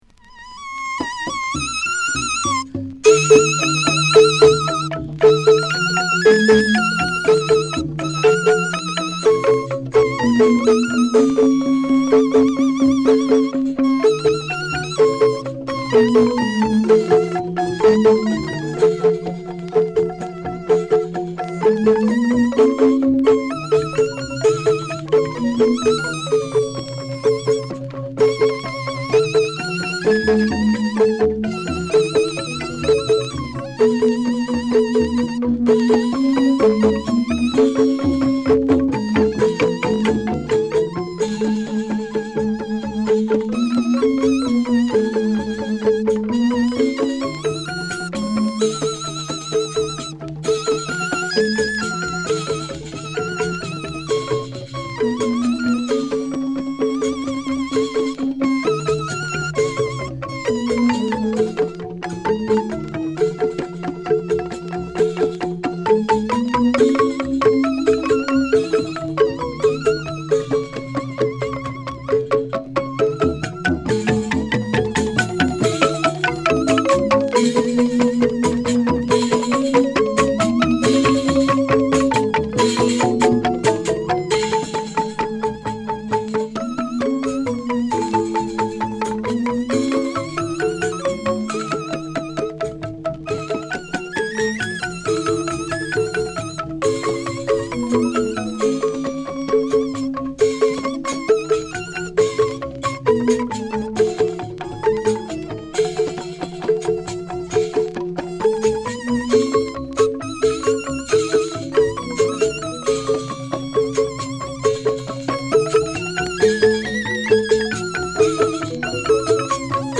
ガムランを中心とした全10曲。金属音や木琴の反復がミニマルに響く抑揚と熱量でトリップ感もある傑作です。